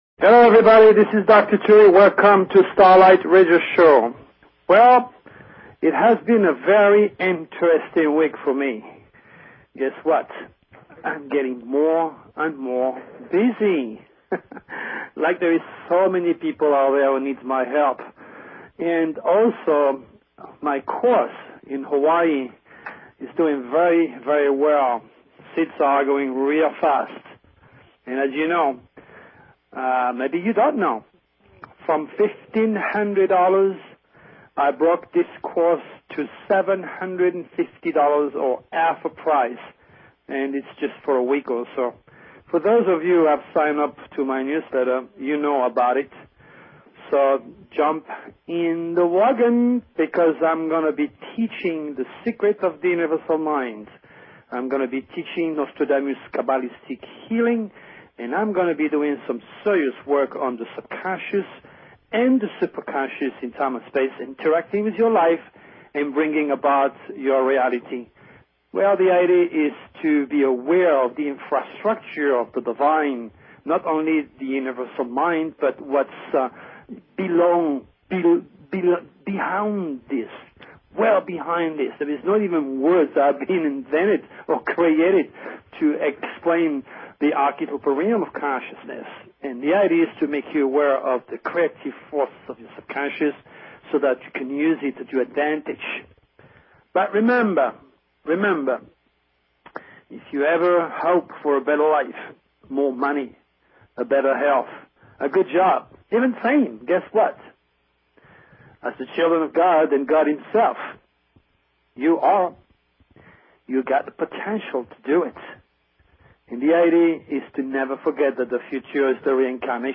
Talk Show Episode, Audio Podcast, Starlight_Radio and Courtesy of BBS Radio on , show guests , about , categorized as